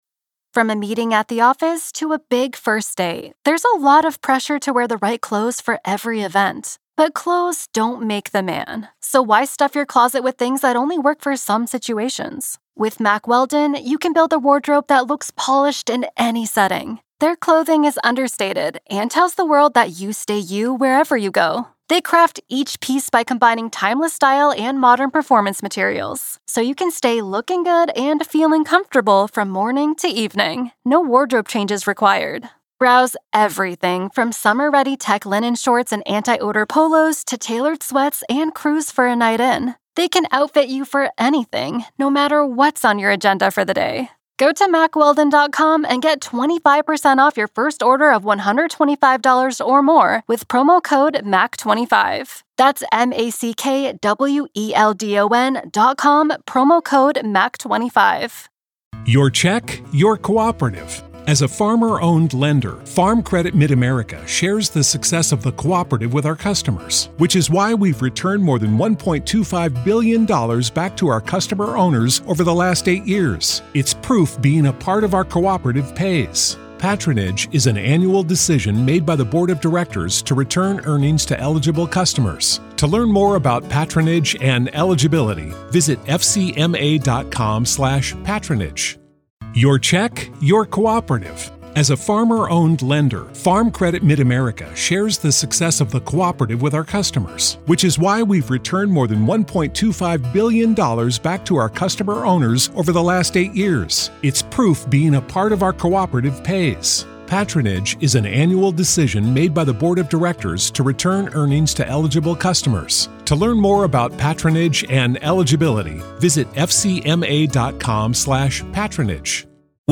True Crime Today | Daily True Crime News & Interviews / Did Diddy Kill Those Who Knew Too Much About Him?